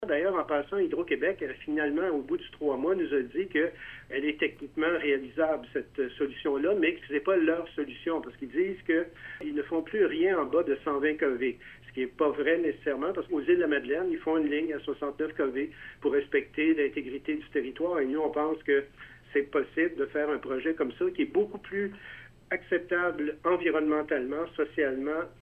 Robert Benoît, maire de Sutton.